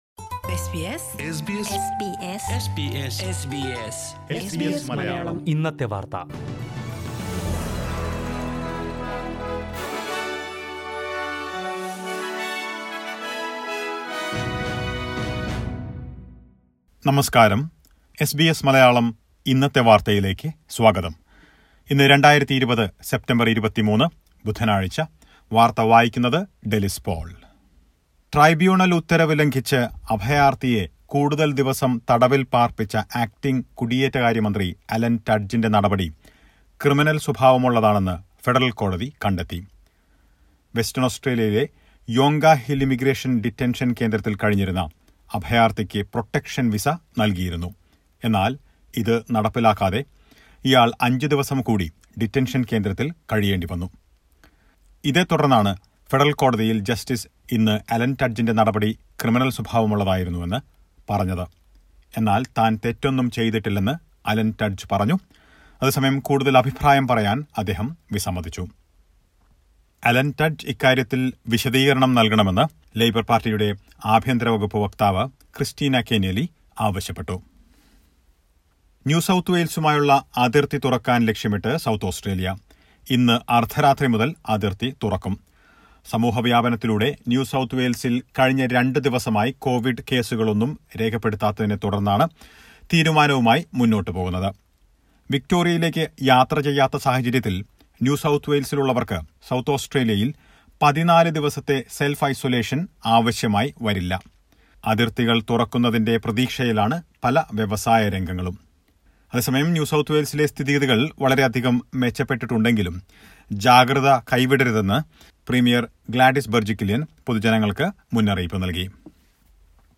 2020 സെപ്റ്റംബർ 23ലെ ഓസ്ട്രേലിയയിലെ ഏറ്റവും പ്രധാന വാർത്തകൾ കേൾക്കാം...